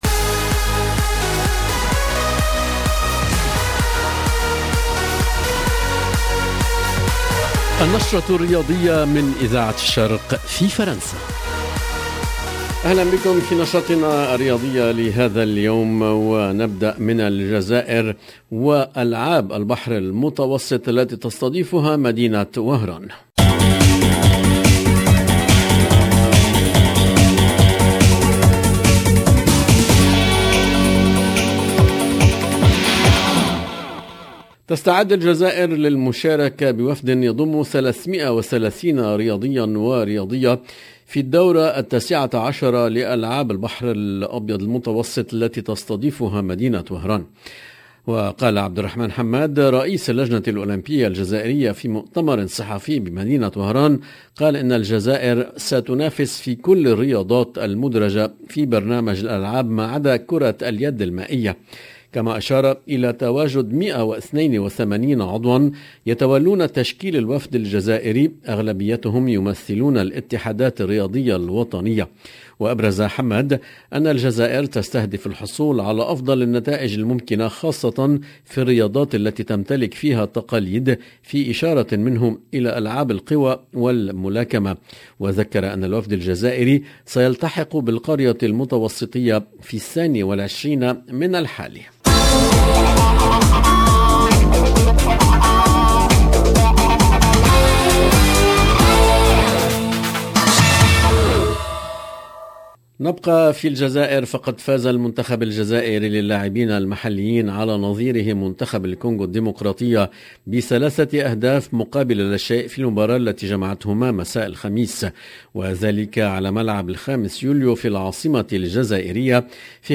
sport 10 juin 2022 - 11 min 10 sec Radio Orient Sport LB Radio Orient Sport Radio Orient Sport Dans notre journal du sport de ce vendredi nous faisons un tour par Oran en Algérie pour parler de la 19 été éditions des jeux méditerranéens et nous ferons le point sur les qualifications pour la CAN 2023 0:00 11 min 10 sec